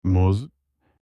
تلفظ صحیح کلمه «موز» در ترکی استانبولی
banana-in-turkish.mp3